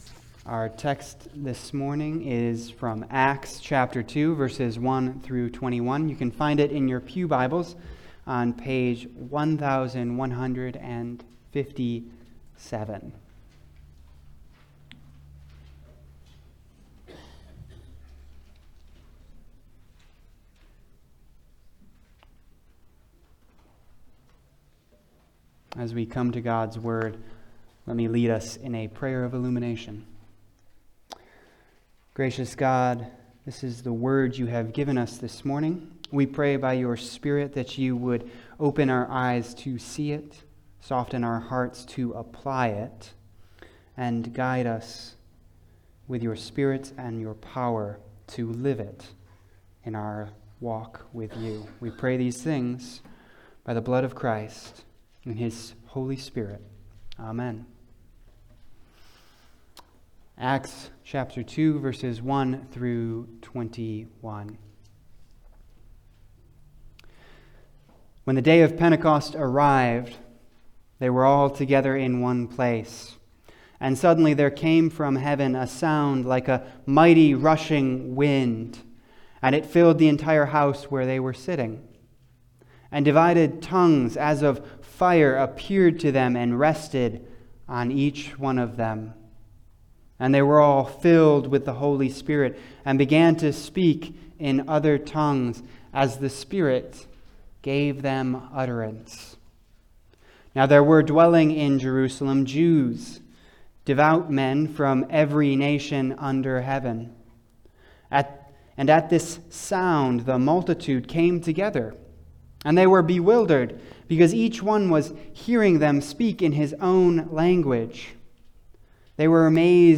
Passage: Acts 2:1-21 Service Type: Sunday Service